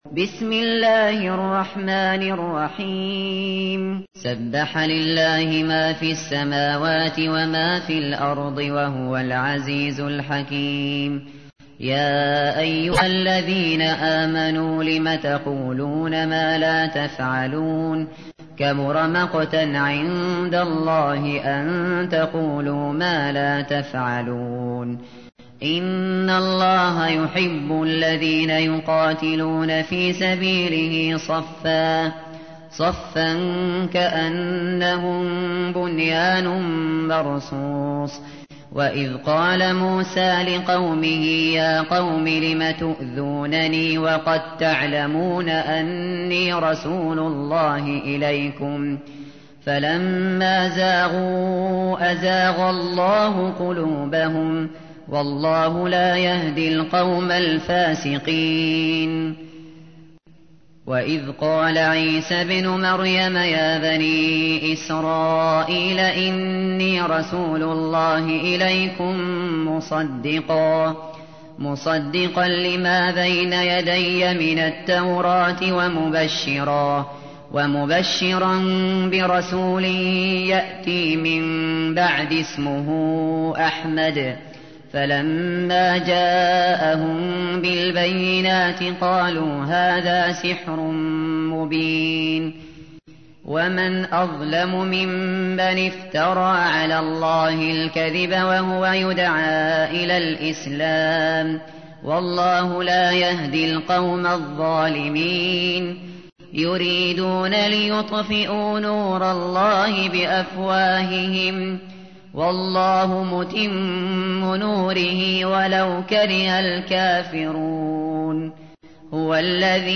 تحميل : 61. سورة الصف / القارئ الشاطري / القرآن الكريم / موقع يا حسين